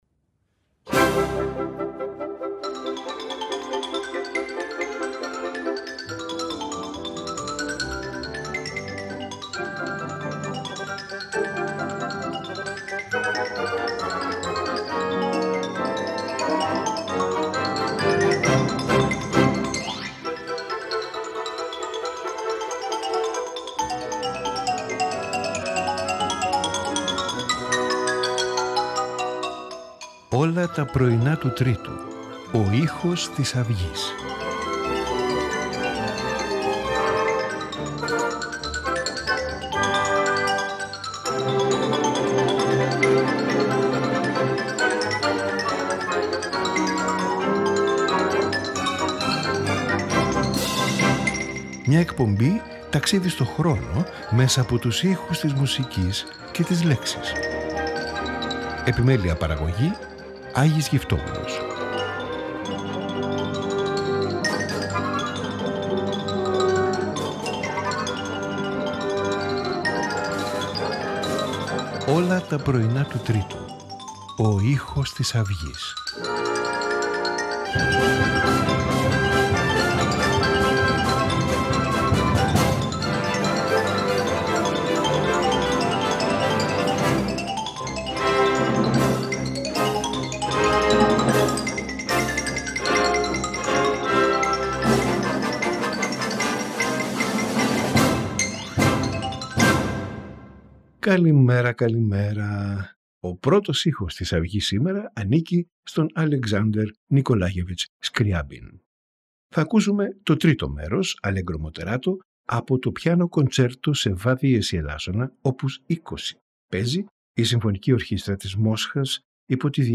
Alexander Nikolayevich Scriabin – Piano Concerto in F-sharp minor, Op.20Antonio Vivaldi – Concerto for 2 Mandolins in G, RV 532W.A. Mozart – Piano Trio in E, K.542